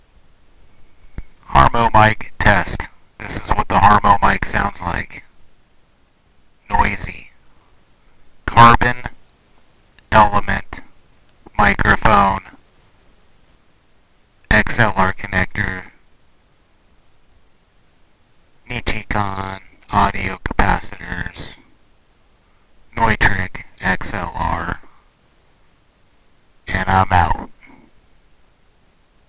Built using vintage 1950s Military carbon microphone capsules, Neutrik XLR connectors, and housed in a thick, custom 3D printed body. The HARMO is a loud and crunchy p48 powered microphone in a cup-able harmonica mic form factor. Inside, I use only metal film resistors and Nichicon audio capacitors helping to create a truly unique sound.
HARMO-mic-test.mp3